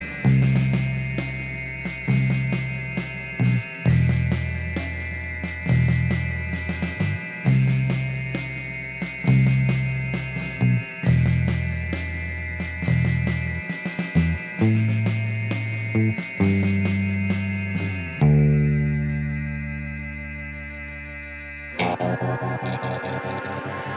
Minus Guitars Pop (1980s) 4:54 Buy £1.50